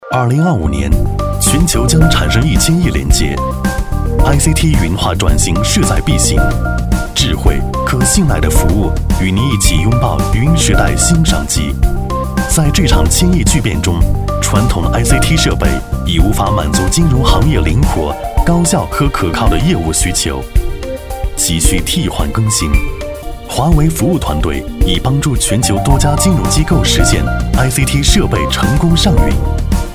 轻松自然 品牌宣传片
大气时尚，洪亮质感男音。